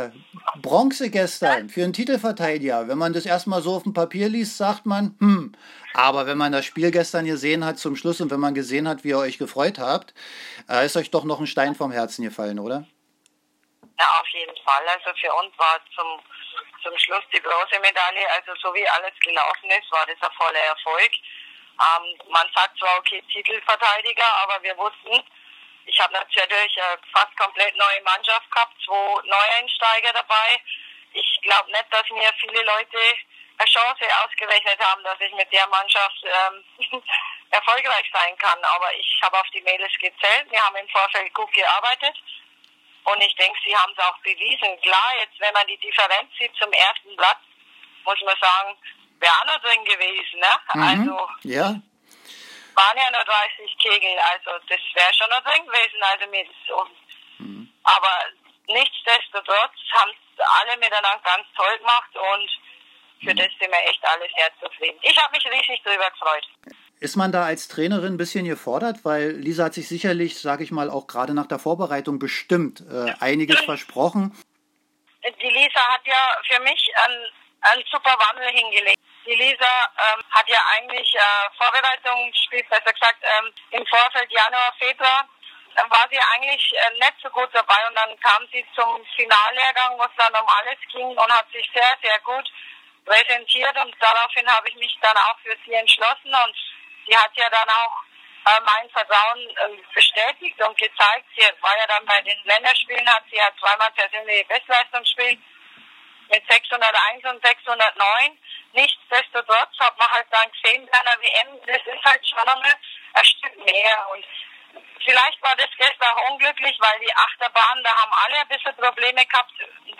Während der VI. Einzel-WM sprachen wir im WM-Studio mit dem